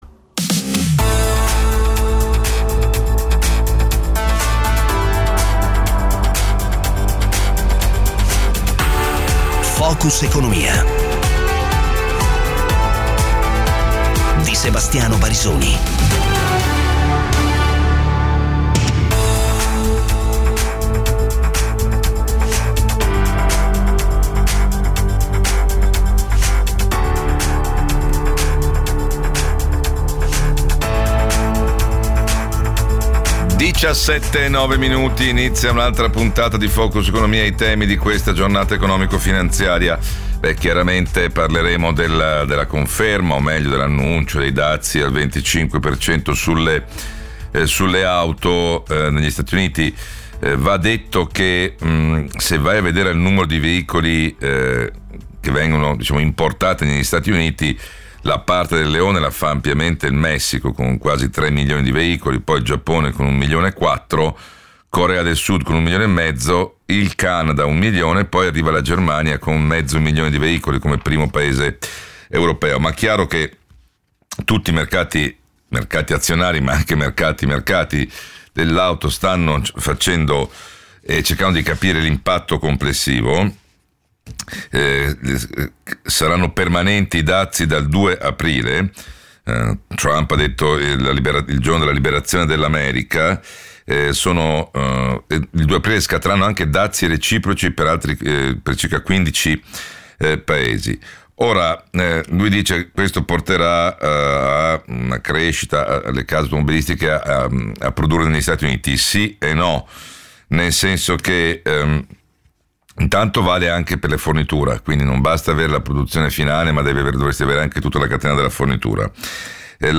Focus economia è il programma quotidiano dedicato all'approfondimento dei temi dell'attualità dell'economia e della finanza, realizzato con i protagonisti della giornata economico finanziaria e il contributo di giornalisti e analisti de Il Sole 24 ORE. L'obiettivo della trasmissione è di spiegare e analizzare, in termini comprensibili anche "ai non addetti ai lavori", i temi più interessanti della giornata. Appuntamento fisso per i commenti a caldo pochi minuti dopo la chiusura della Borsa.